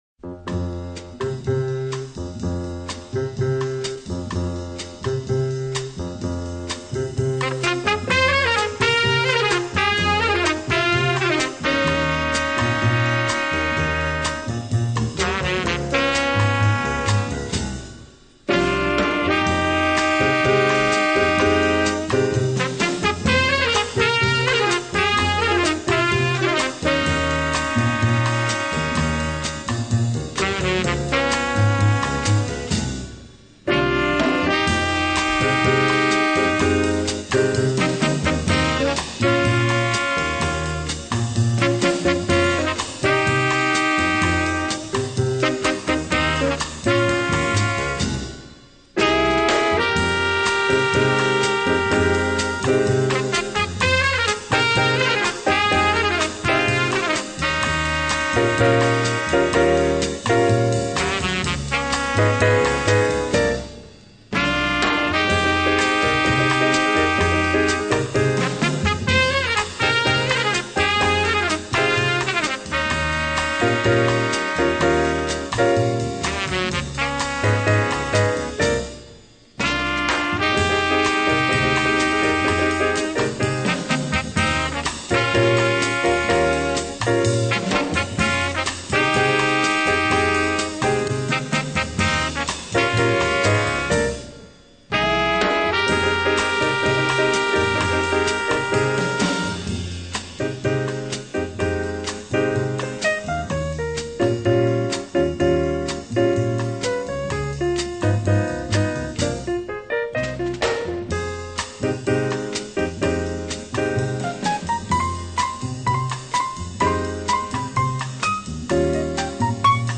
音乐静静地流淌着，一丝不乱。